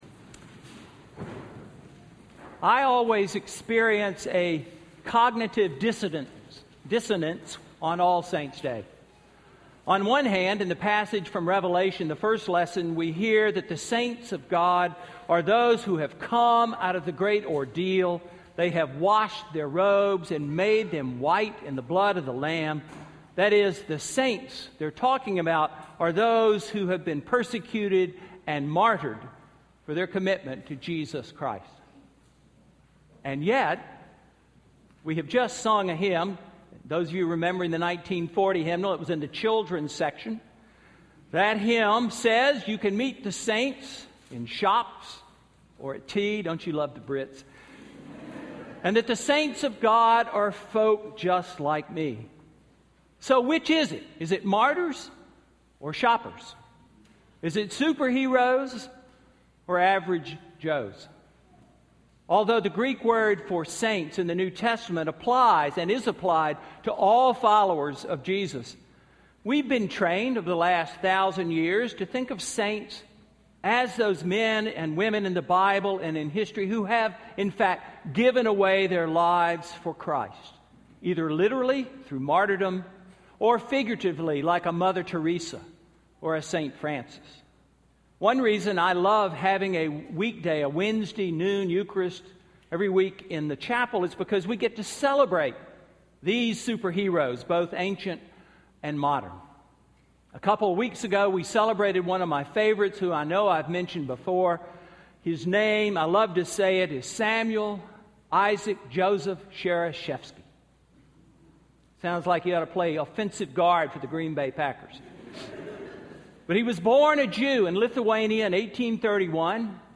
Sermon–November 2, 2014